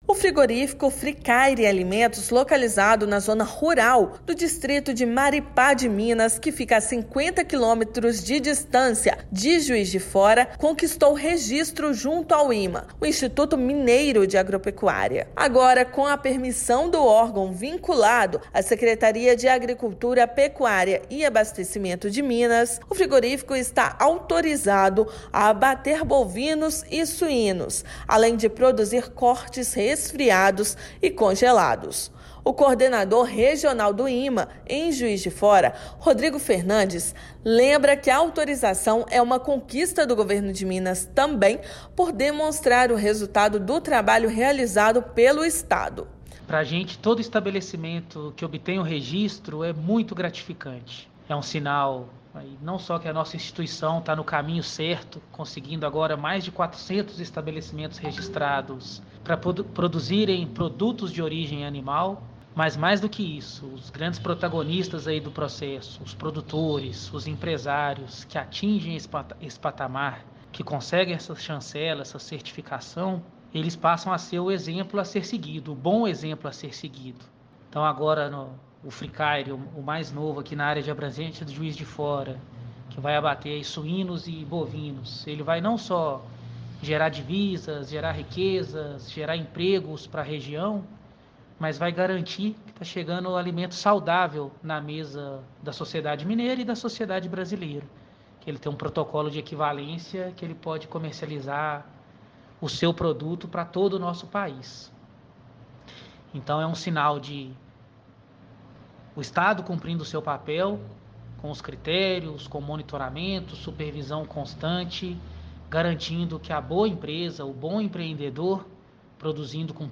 [RÁDIO] Frigorífico planeja aumentar vendas e conquistar novos mercados com registro do IMA
Com o selo do instituto, empresa que abate suínos e bovinos também pretende aperfeiçoar a produção. Ouça matéria de rádio.